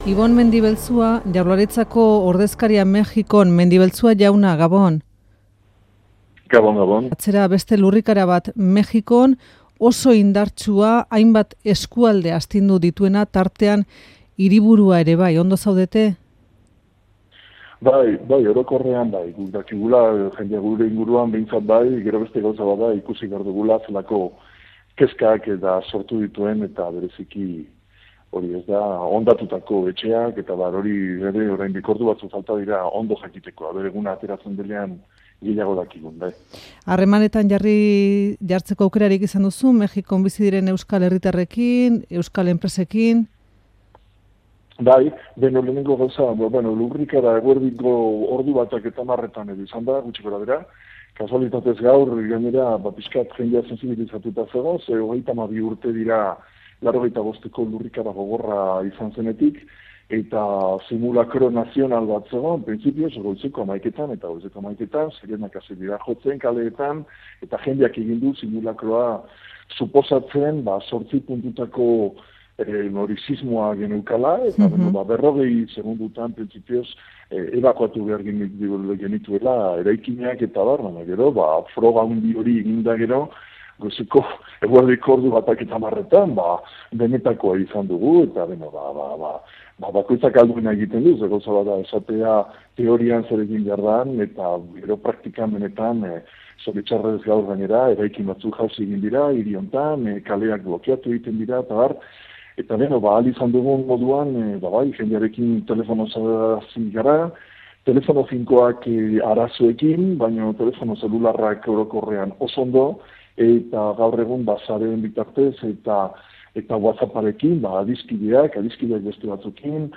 Ibon Mendibelzua Eusko Jaurlaritzak Mexikon duen ordezkariak gertutik sentitu du herrialdea astindu duen lurrikara. Azken ordua zuzenean eman du Mexikotik.